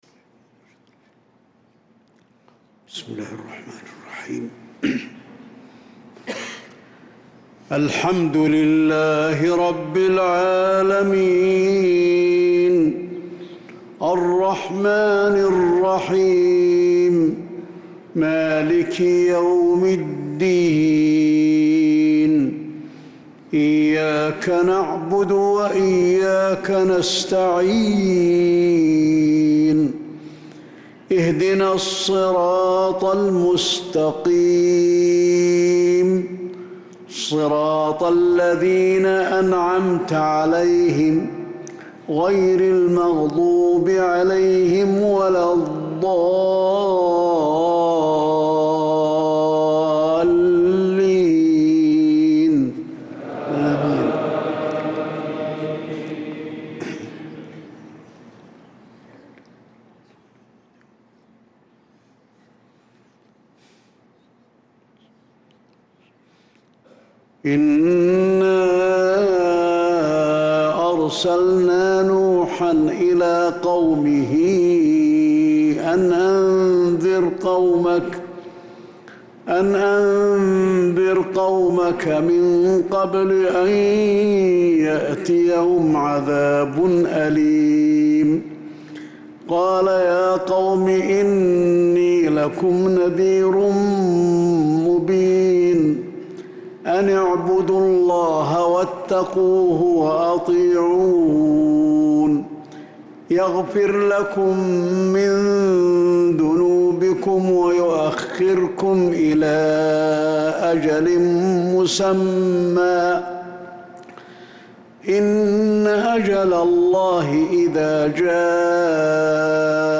صلاة الفجر للقارئ علي الحذيفي 18 ذو القعدة 1445 هـ